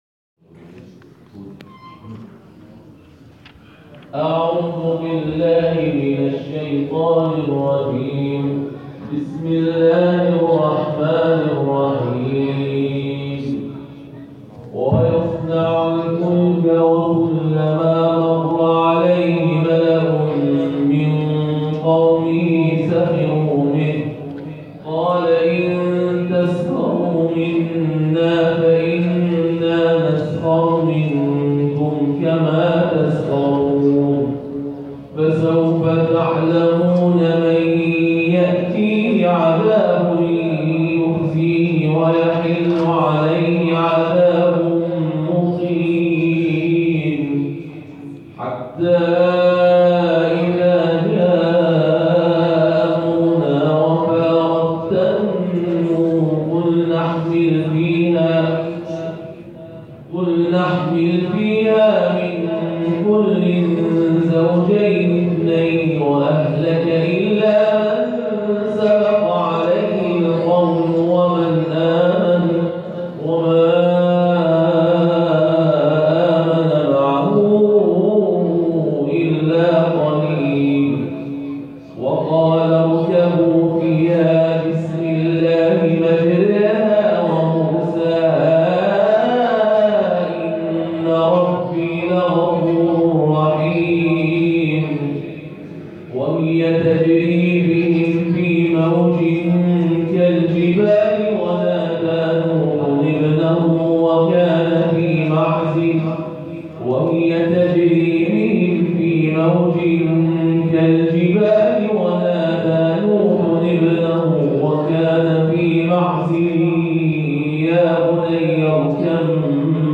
جلسه آموزشی حفظ و قرائت قرآن مؤسسه کریمه برگزار شد+ تلاوت
تلاوت